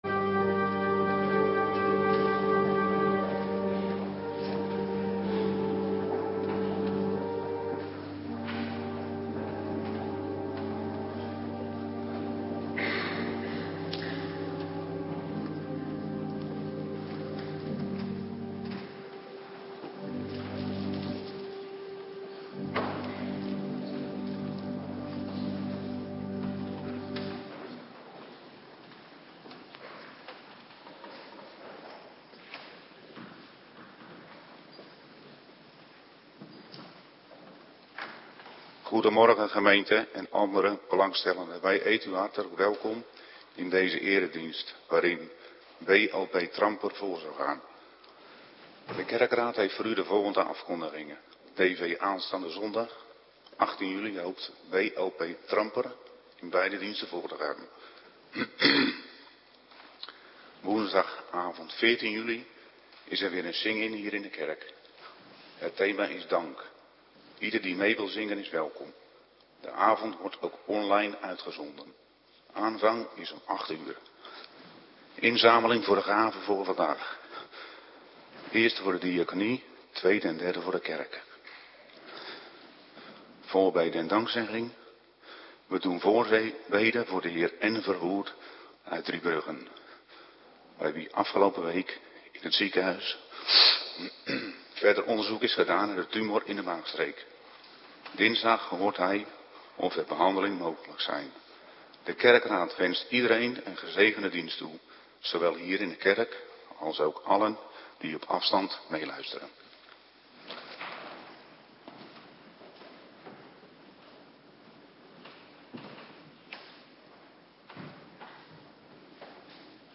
Morgendienst - Cluster B
Locatie: Hervormde Gemeente Waarder